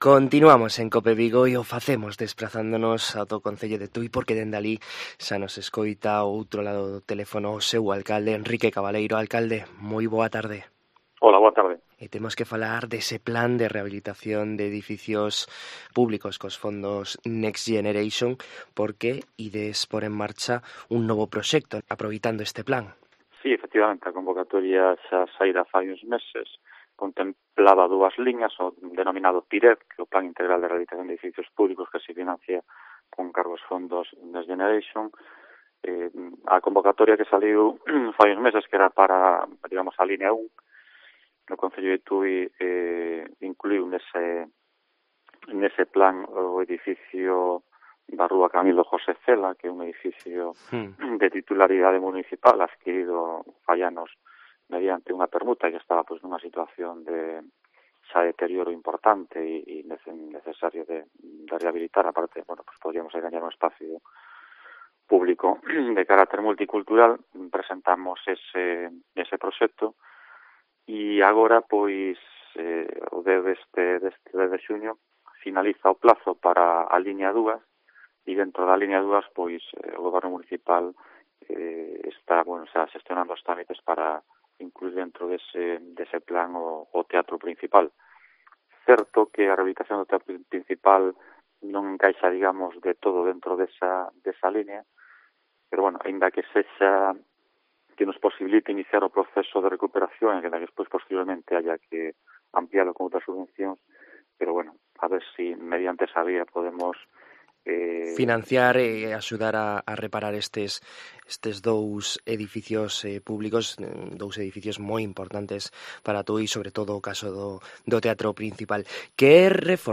En COPE Vigo falamos co alcalde de Tui, Enrique Cabaleiro, para coñecer a actualidade local deste municipio do sur da provincia de Pontevedra